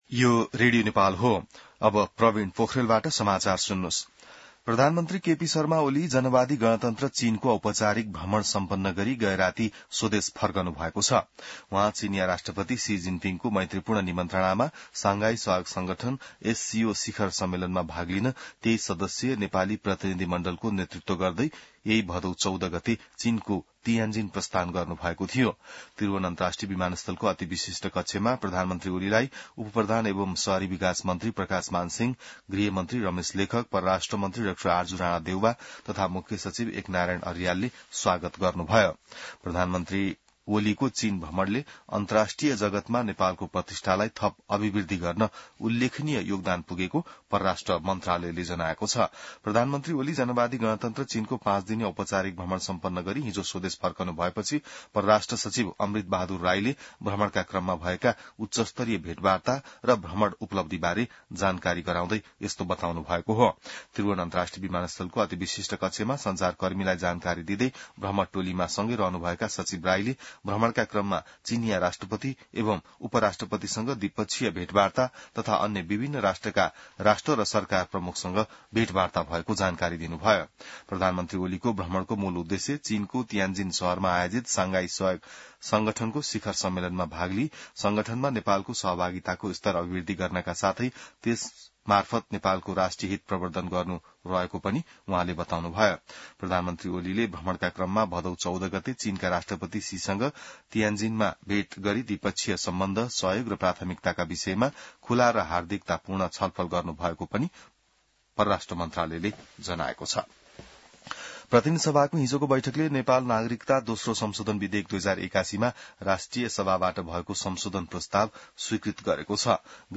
बिहान ६ बजेको नेपाली समाचार : १९ भदौ , २०८२